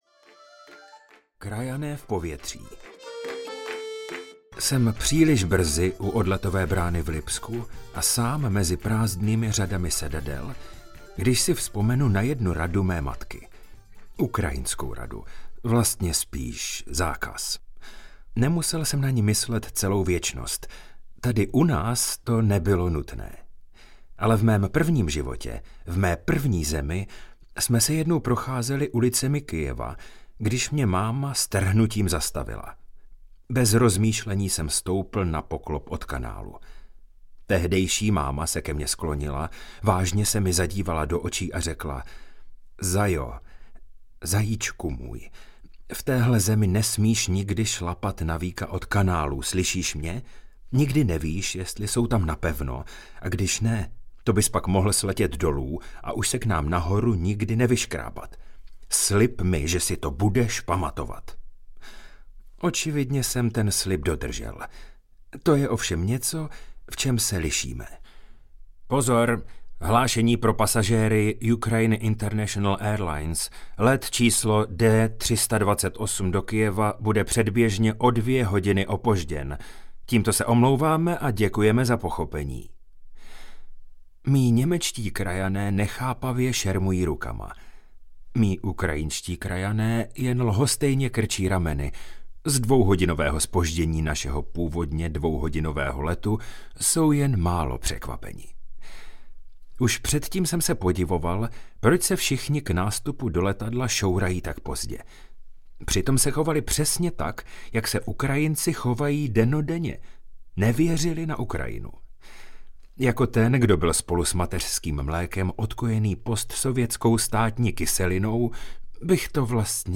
Formalita v Kyjevě audiokniha
Ukázka z knihy